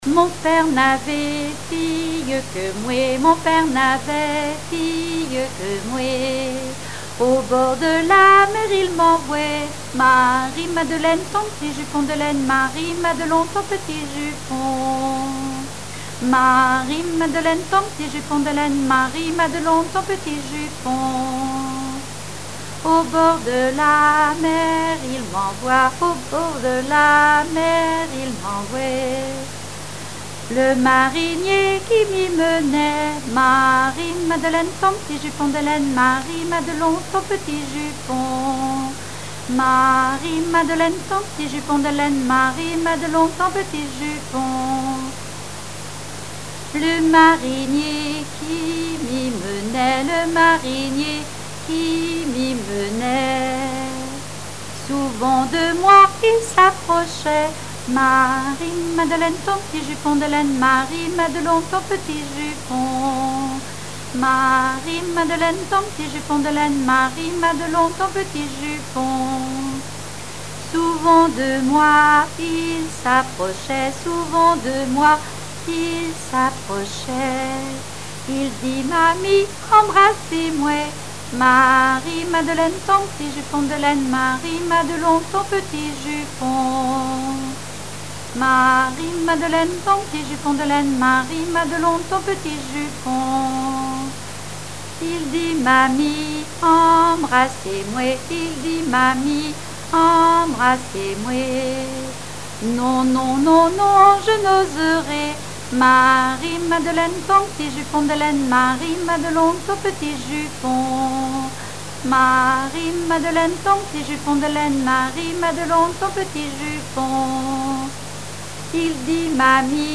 Aiguillon-sur-Vie (L')
répertoire de chansons populaire et traditionnelles
Pièce musicale inédite